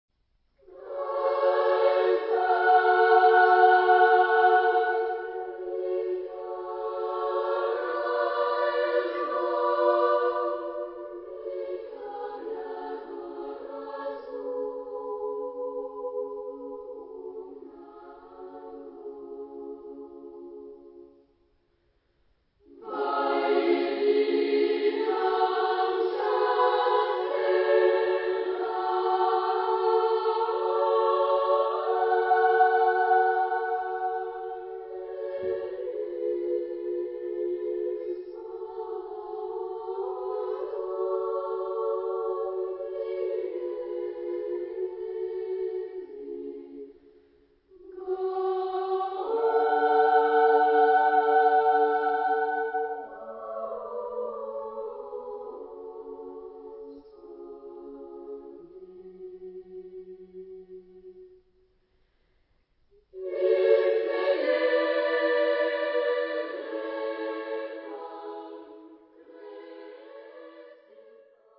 Genre-Style-Forme : Sacré ; Messe ; Orthodoxe
Tonalité : fa dièse mineur